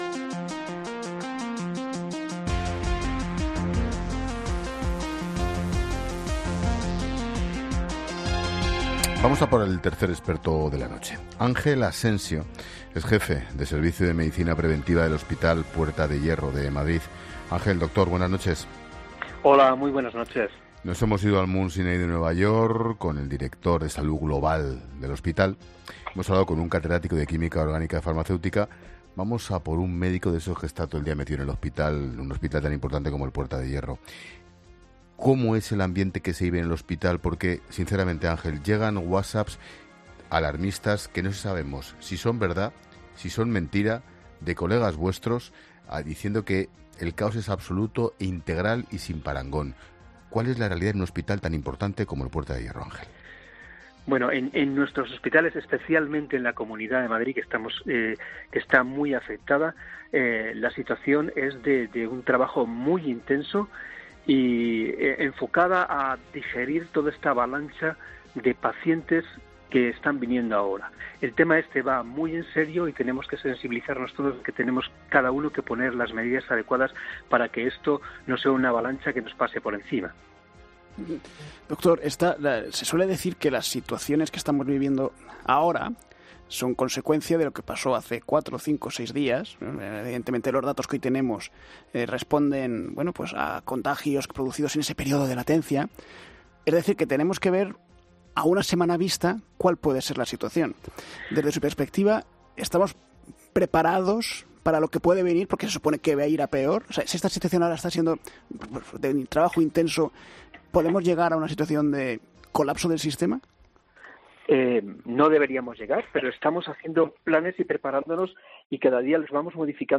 Un médico de Madrid en COPE: "Que los ciudadanos vayan al hospital cuando tengan síntomas graves"